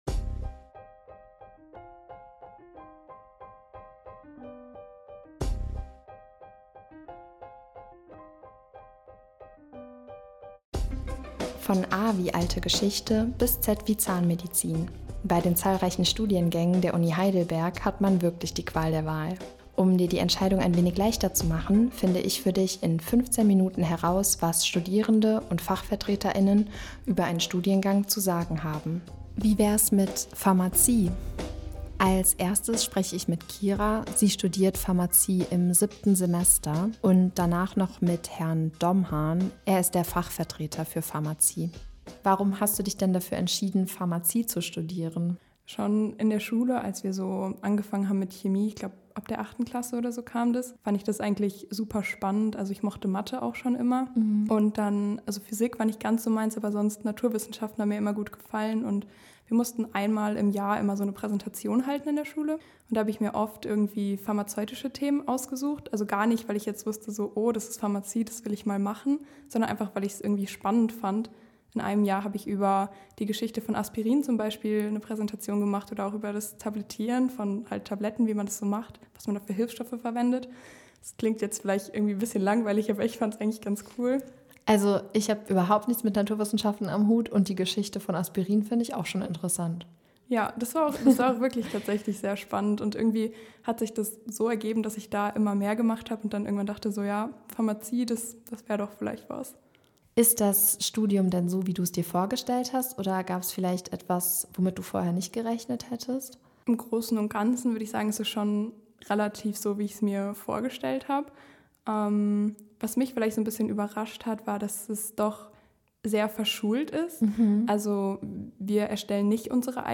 Um dir die Entscheidung ein wenig leichter zu machen, finde ich für dich in 15 Minuten heraus, was Studierende und Fachvertreter*innen über einen Studiengang zu sagen haben.